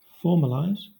Ääntäminen
Southern England UK : IPA : /ˈfɔː(ɹ).mə.laɪz/